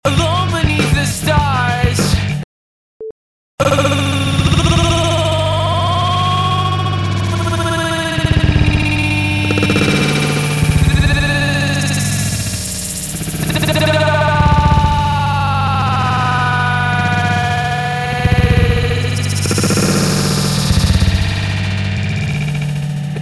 very stuttery